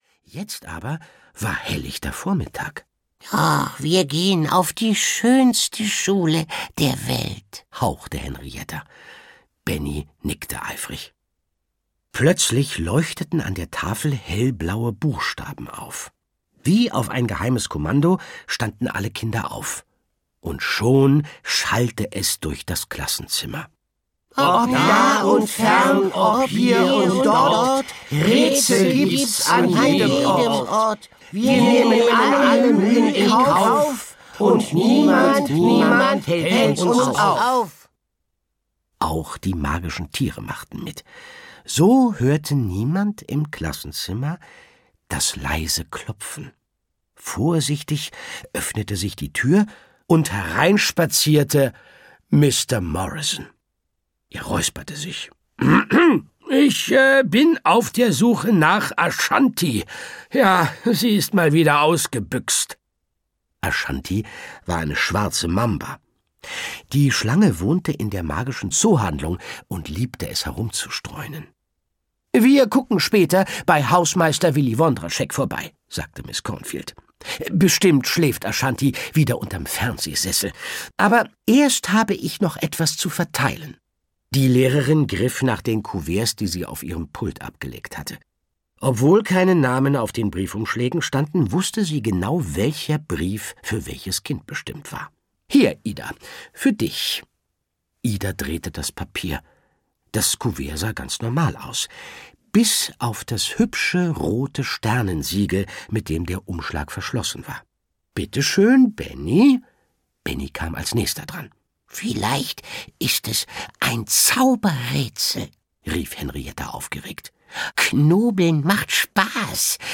Die Schule der magischen Tiere - Endlich Ferien 5: Benni und Henrietta - Margit Auer - Hörbuch